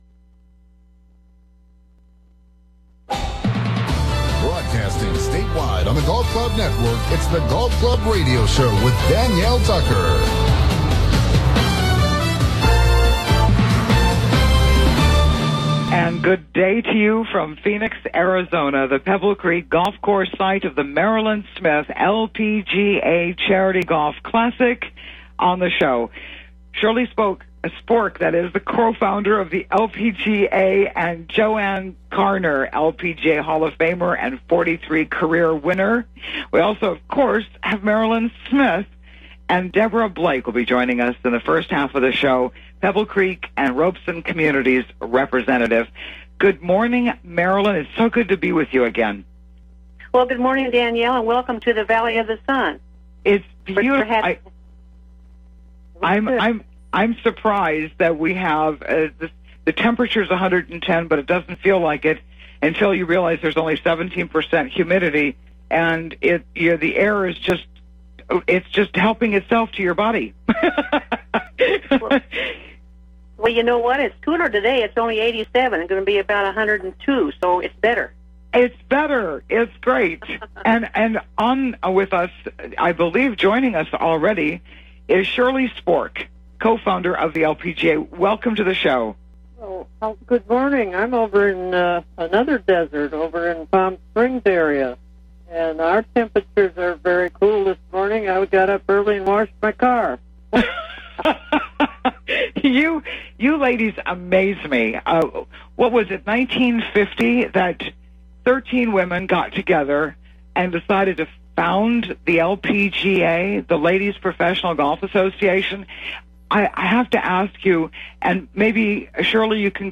The Golf Club Radio Show� broadcasting state-wide talking to Hawaii's Golf Pros and across America sports shrinks, authors, mental coaches and PGA broadcasters.
Live from Phoenix at The Marilynn Smith LPGA Charity Golf Tournament